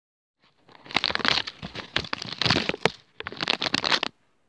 Een reep chocola openmaken?